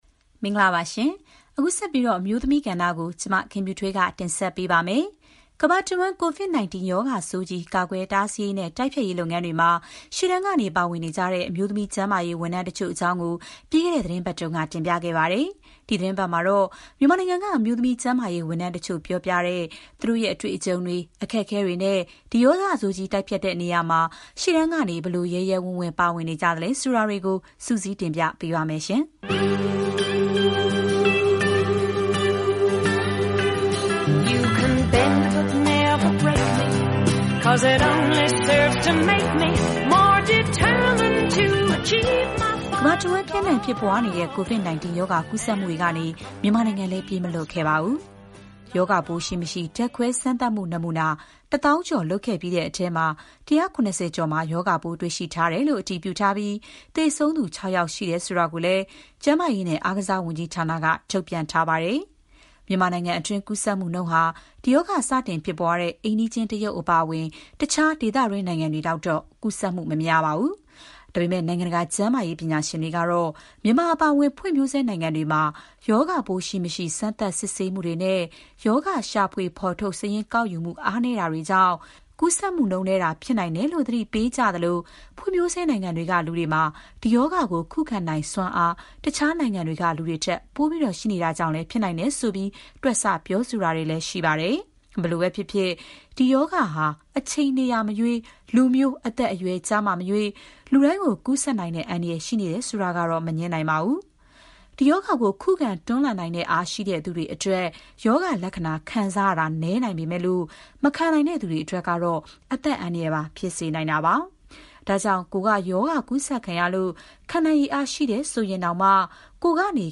အမျိုးသမီး ကျန်းမာရေးဝန်ထမ်းတွေ ပြောပြတဲ့ သူတို့ရဲ့ အတွေ့အကြုံတွေ